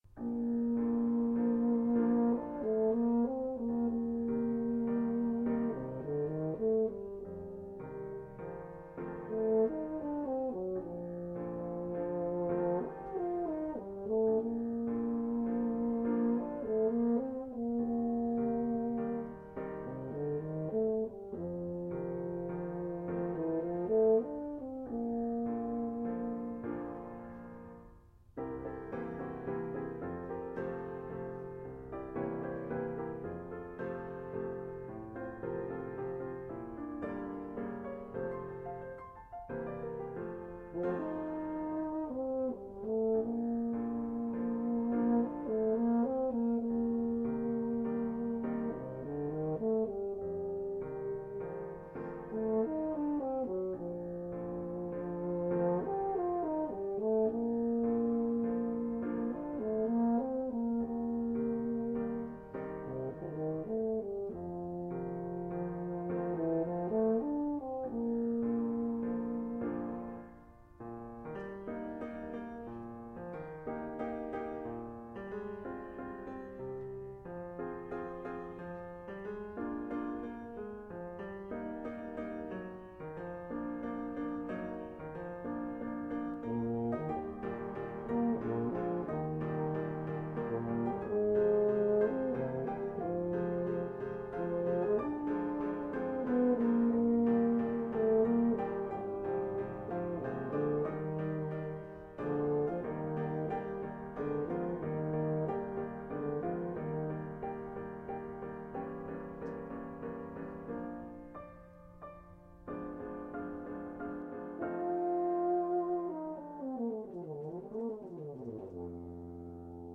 For Euphonium Solo